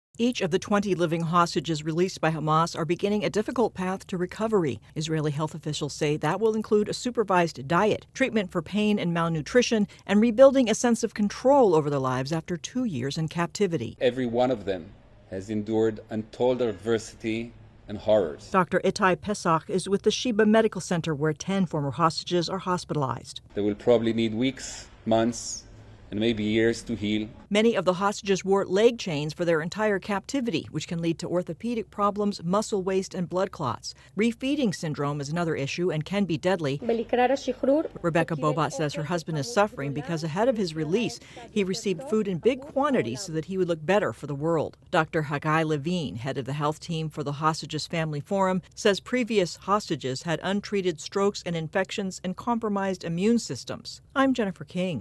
The hostages returned from captivity in Gaza are in stable condition but need a team of specialists as they start to recover from their ordeal. AP correspondent